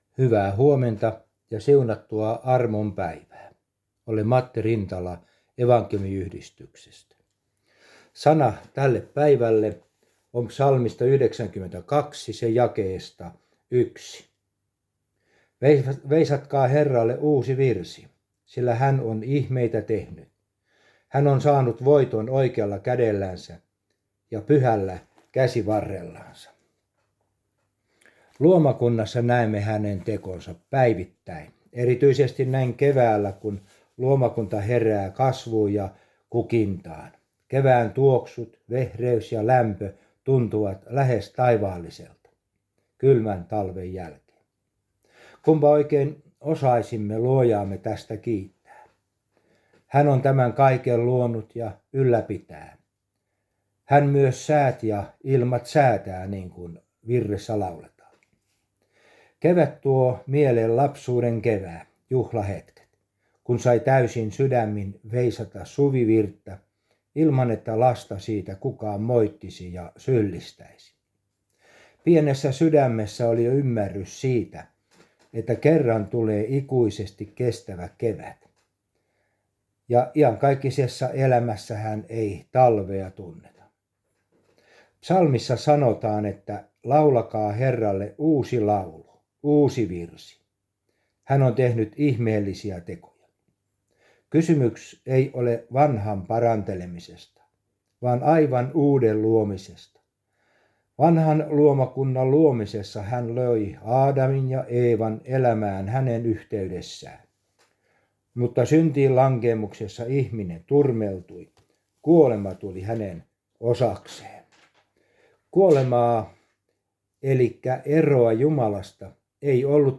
Aamuhartaus Järviradioon 10.5.2023